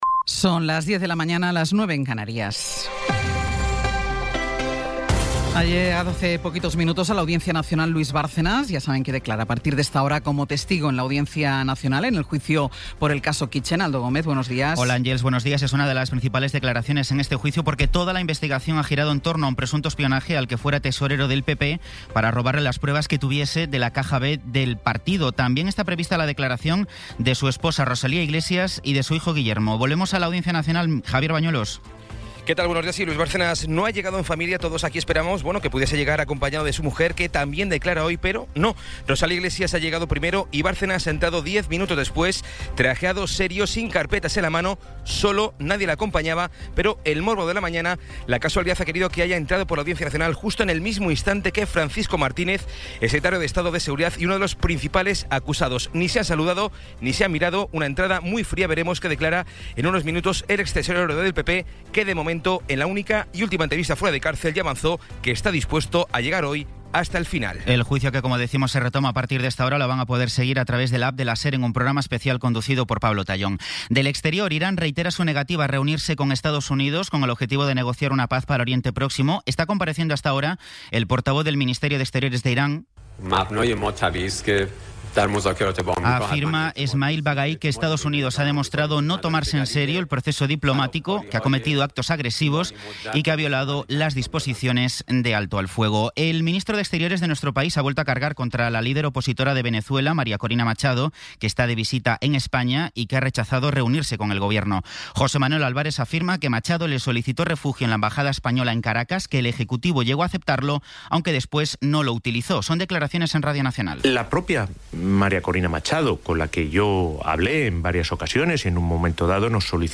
Resumen informativo con las noticias más destacadas del 20 de abril de 2026 a las diez de la mañana.